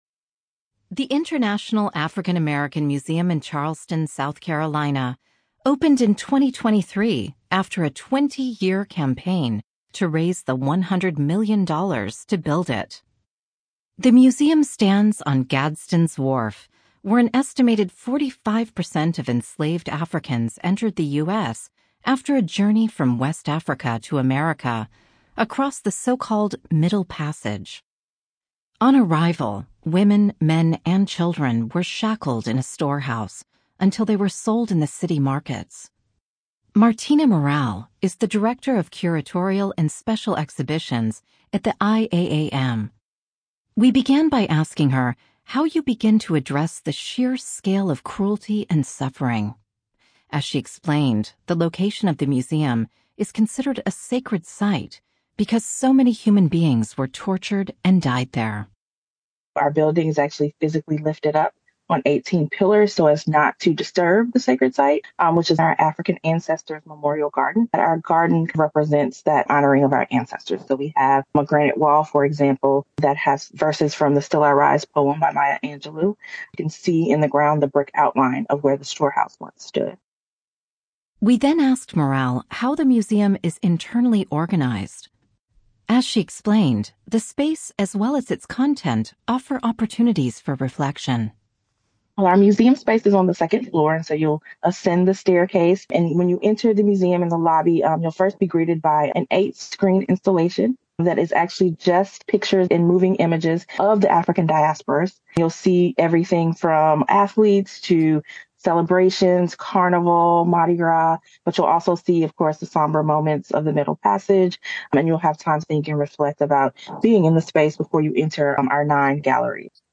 (American accent)